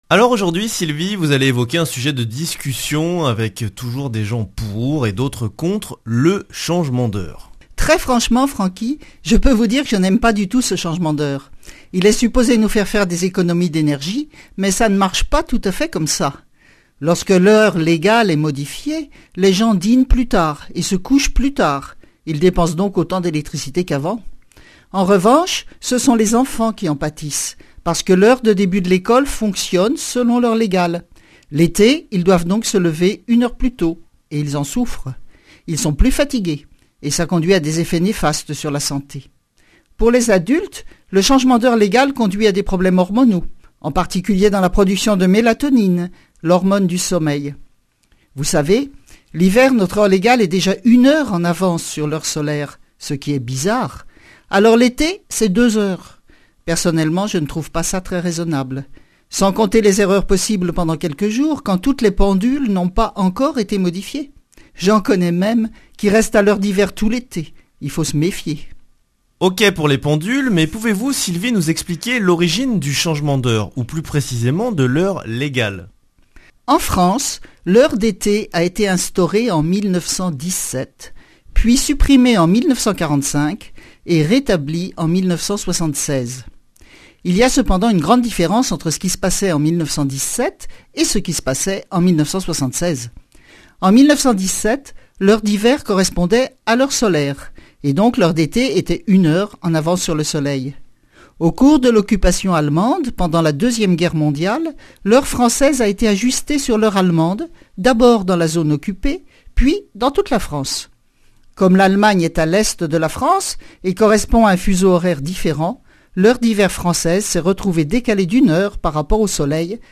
Chronique Astrophysique
Speech